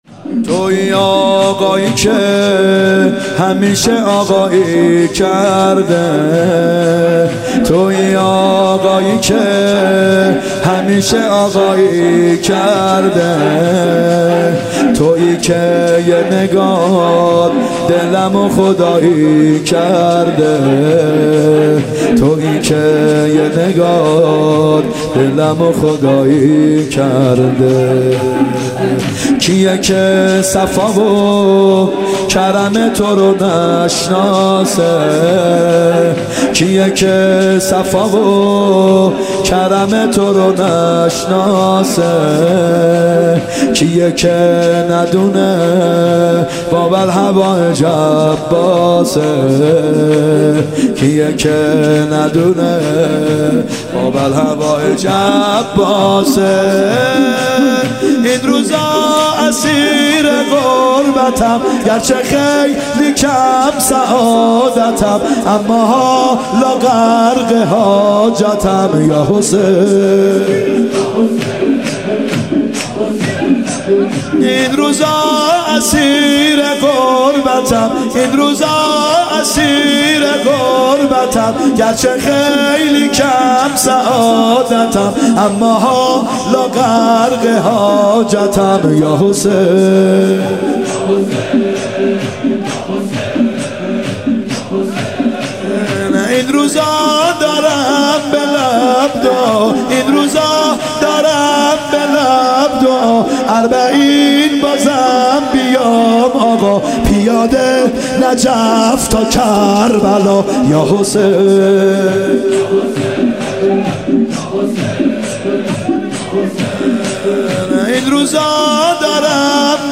محرم 94 شب هفتم شور
محرم 94(هیات یا مهدی عج)